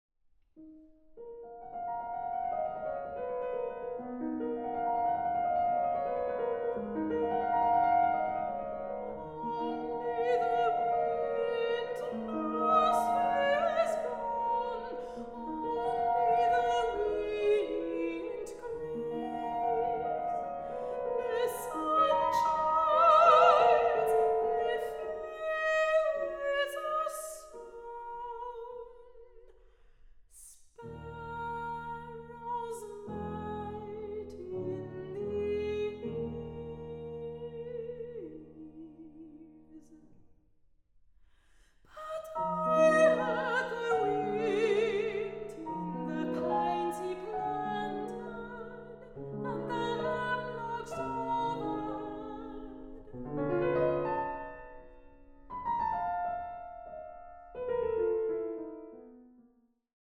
Sopran
Klarinette
Klavier